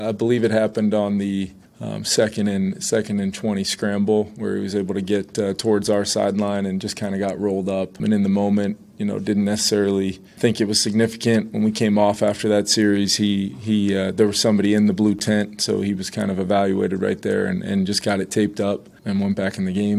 Head coach Kevin O’Connell says McCarthy suffered an ankle injury in Sunday’s 22-6 loss to Atlanta.  O’Connell explains when the injury took place.